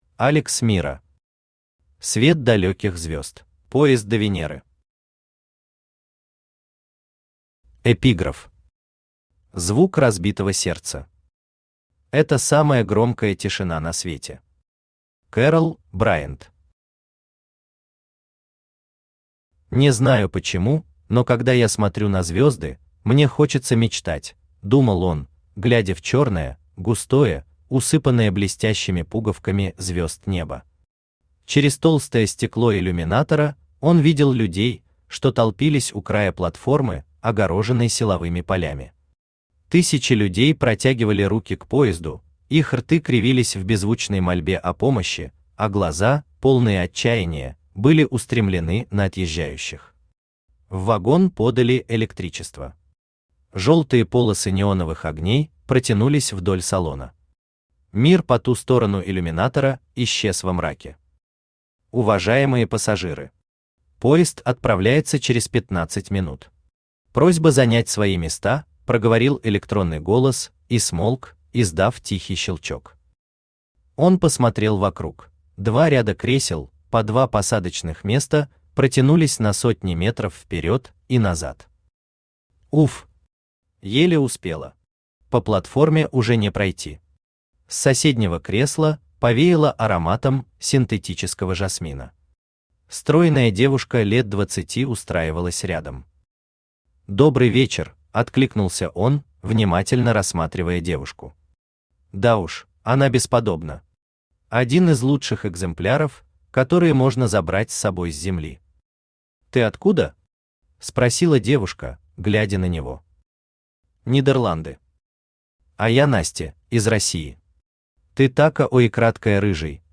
Аудиокнига Свет далеких звезд | Библиотека аудиокниг
Aудиокнига Свет далеких звезд Автор Алекс Миро Читает аудиокнигу Авточтец ЛитРес.